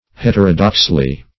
Het"er*o*dox`ly, adv.